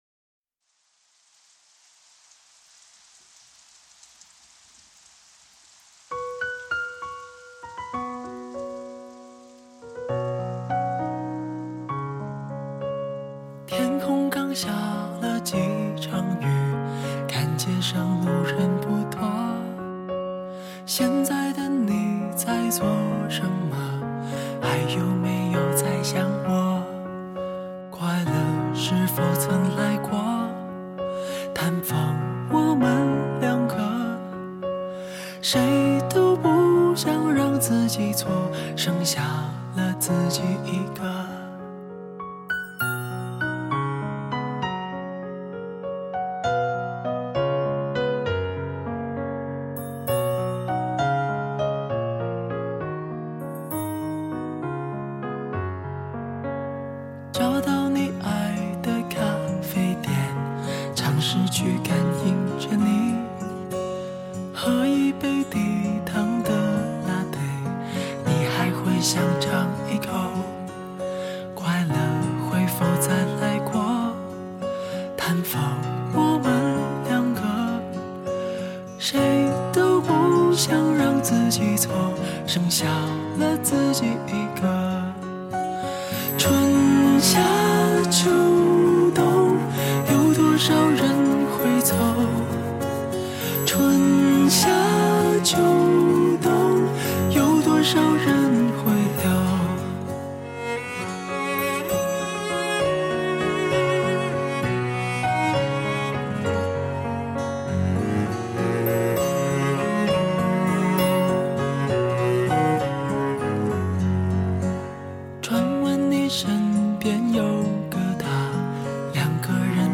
回归到首张专辑的清新民谣，以大家爱听的情歌为主